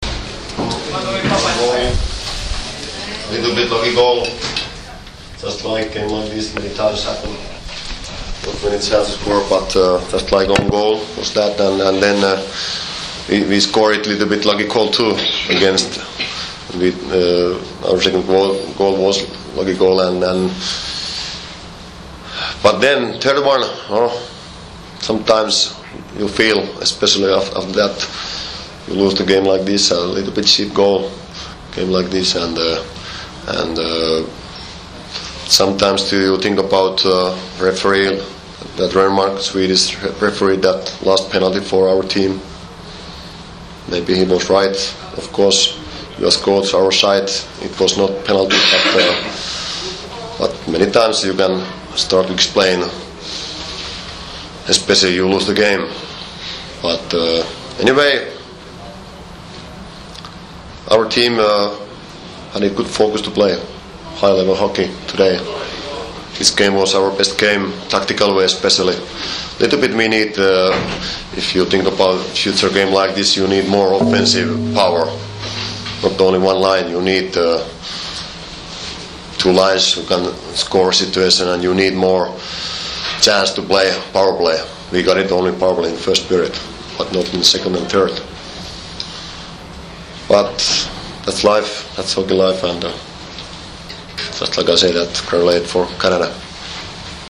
Tiskov� konference